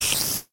sounds / mob / spider / say3.ogg